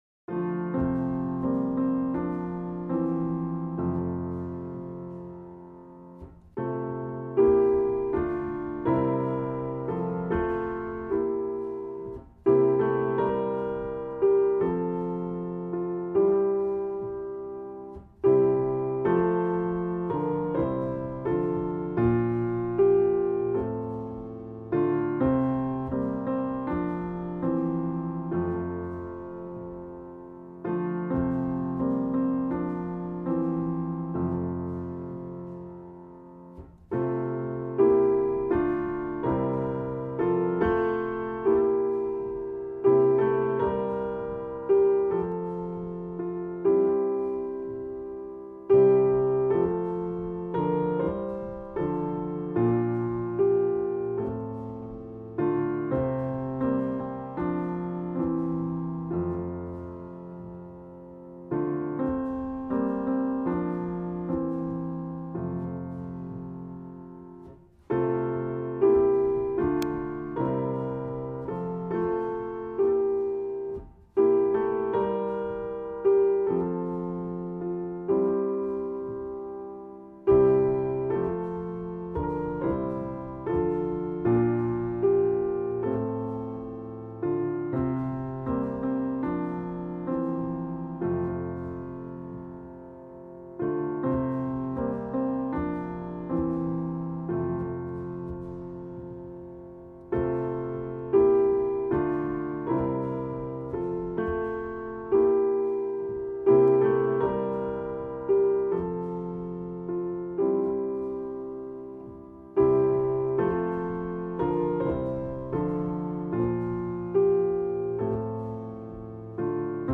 Atem-Ton-Bewegung